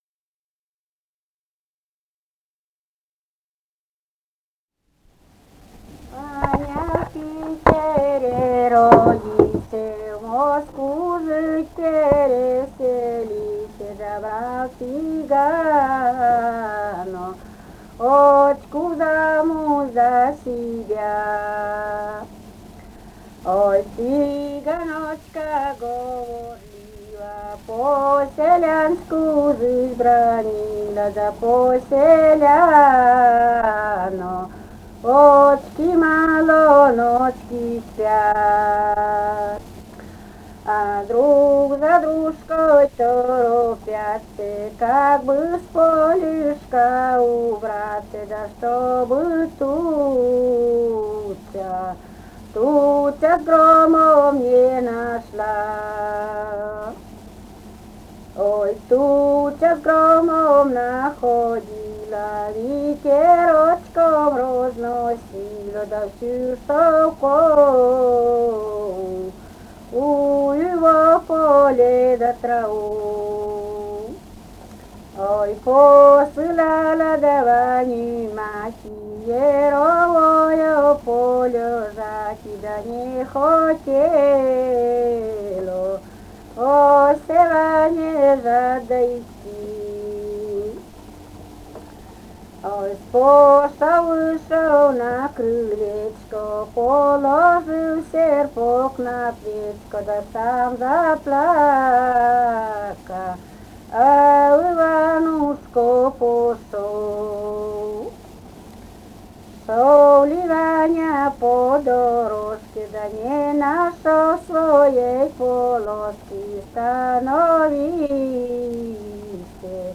«Ваня в Питере родился» (лирическая).